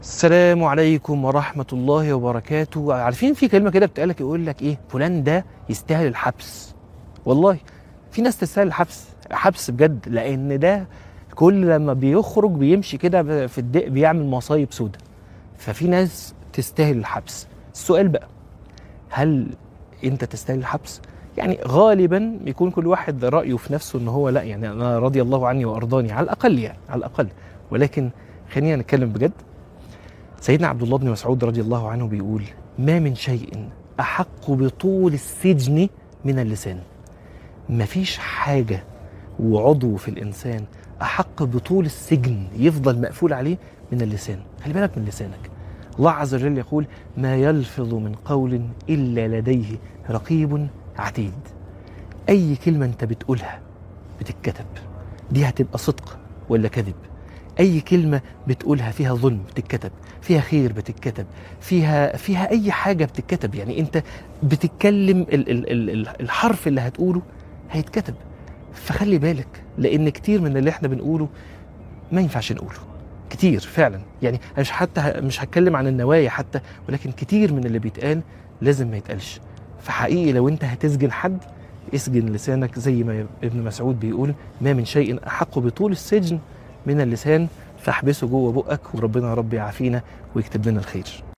عنوان المادة لازم تحبسه - من الحرم